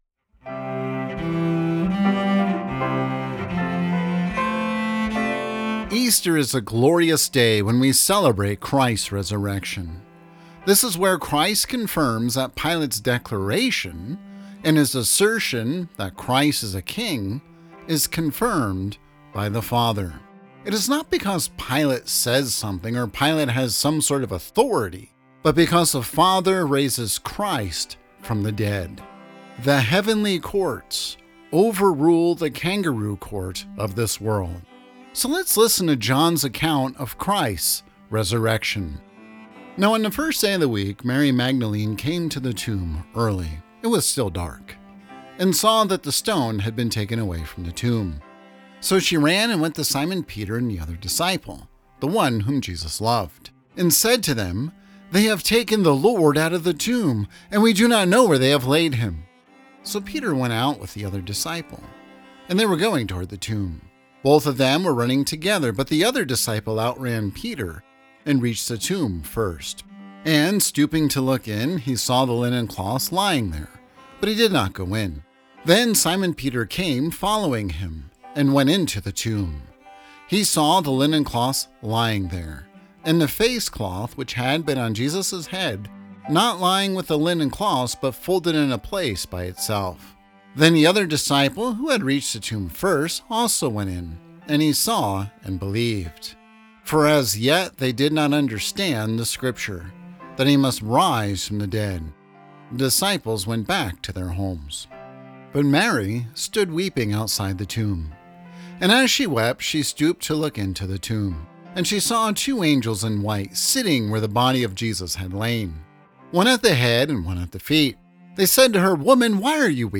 Easter+Reading.m4a